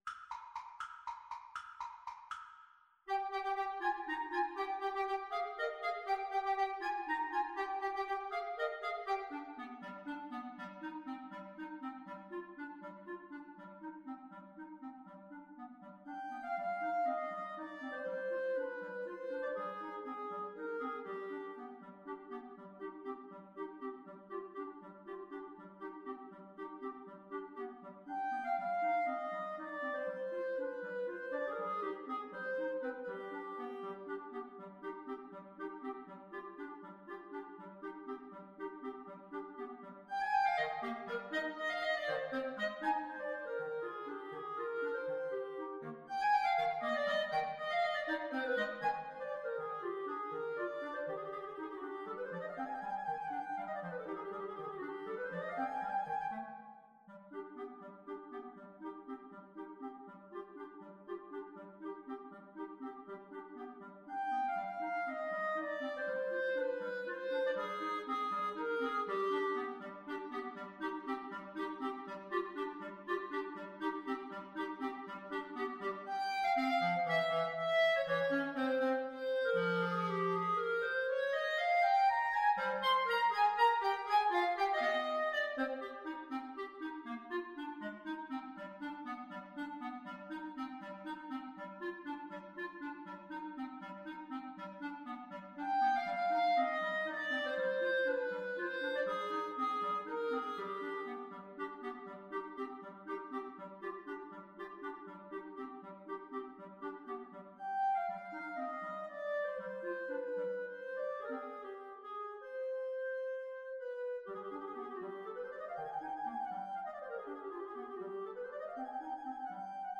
Allegro vivo (.=80) (View more music marked Allegro)
Clarinet Trio  (View more Advanced Clarinet Trio Music)
Classical (View more Classical Clarinet Trio Music)